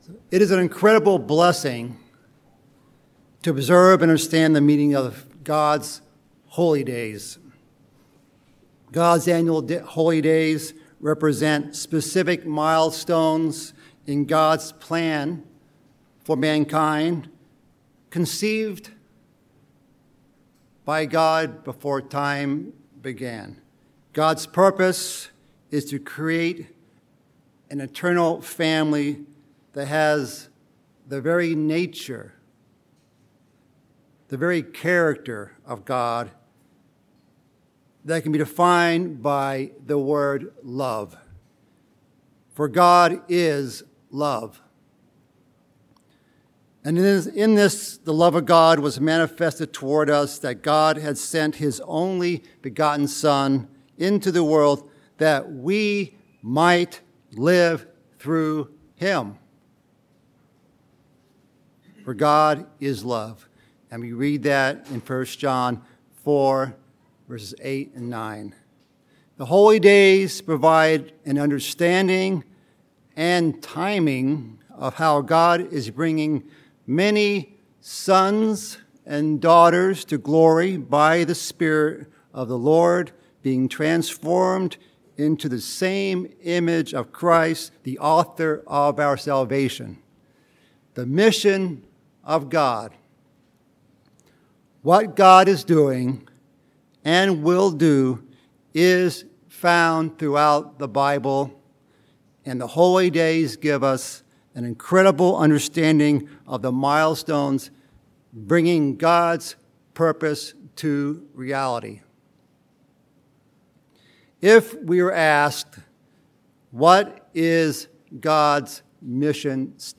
This sermon prepares our minds and hearts to observe with purpose the holy days of the 7th Month.